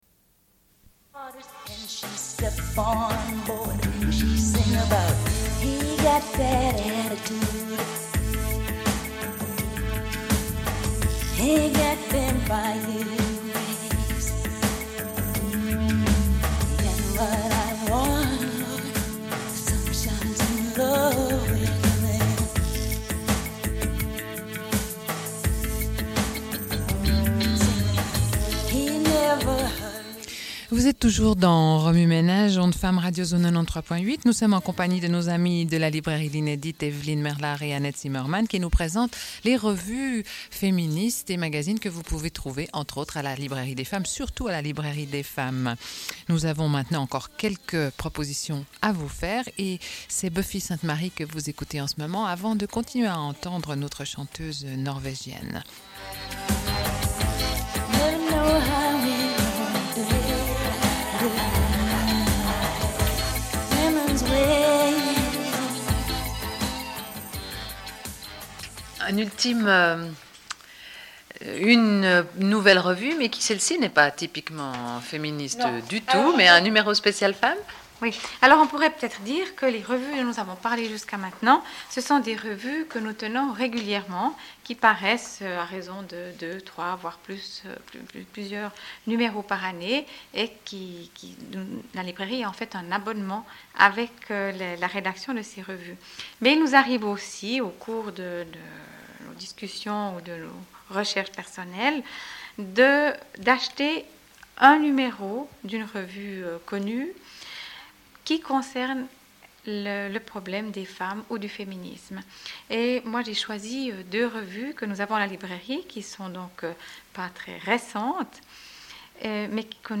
Une cassette audio, face A31:28